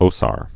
(ōsär)